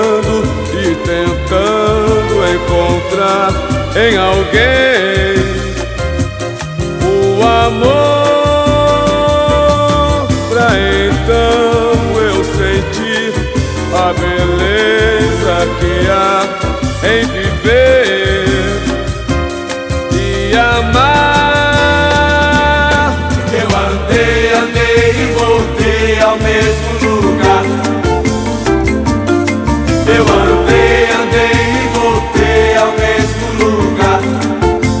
Samba Brazilian MPB